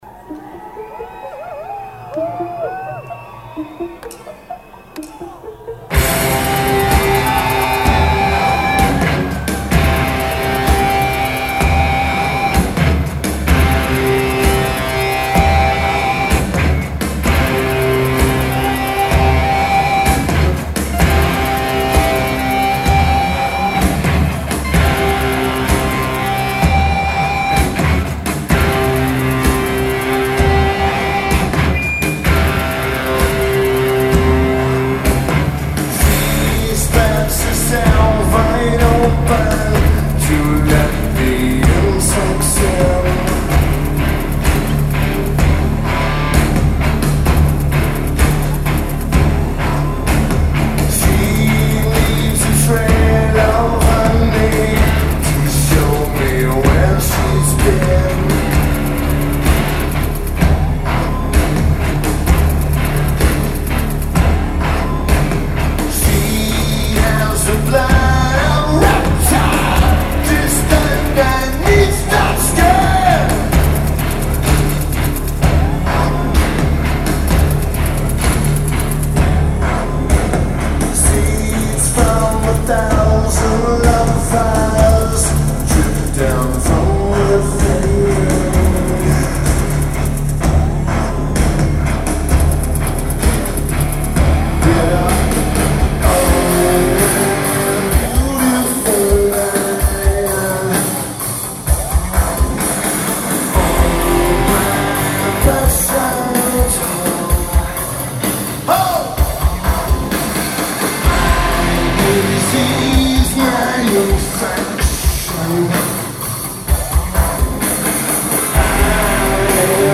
Le Zénith
Paris France